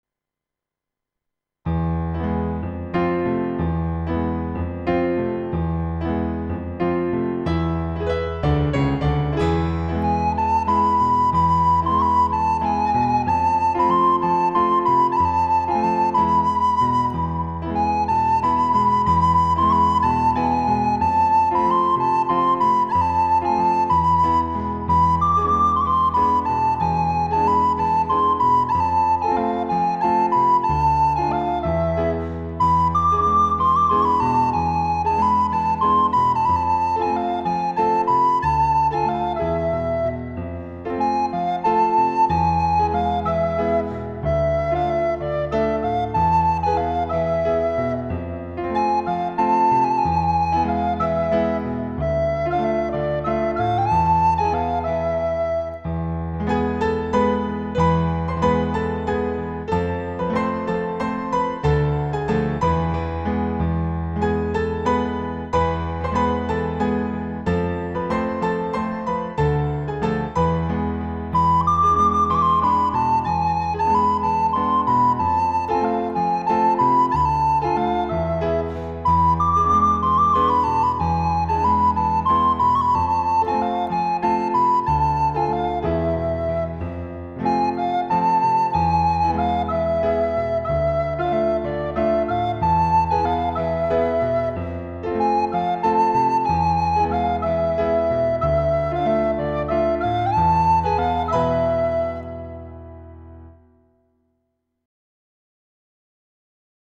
فایل صوتی MP3 اجرای مرجع
• نوازندگانی که به دنبال اجرای پرانرژی و ریتمیک هستند
ایرانی